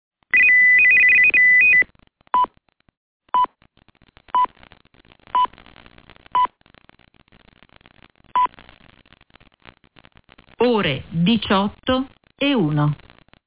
• • • Immagine:Segnale orario.mp3 - Dimenticatoio